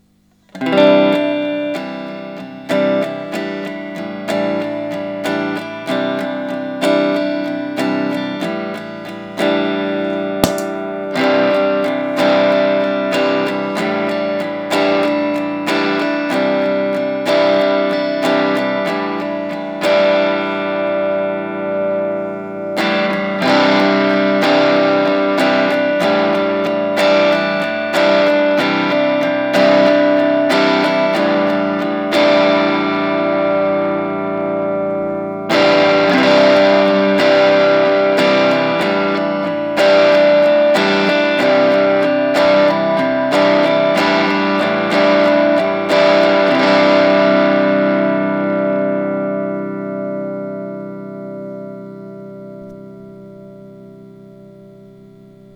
If you push it to the max is has a bit of Fuzz on it as well. Once Fuzzsilla is out in the world this guy will probably be next, a nice warm distortion that can get quite manic and HEAPS of level. This clip was recorded with the mic on my iPhone, you hear alot of the actual guitar, but it gives you an idea, Tele into my Champ. Distortion Clip